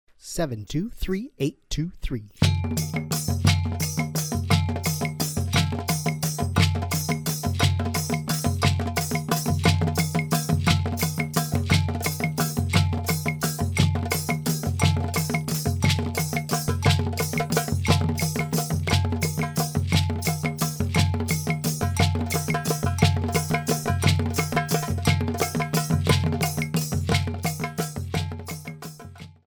Fast Triple Meter - 175 bpm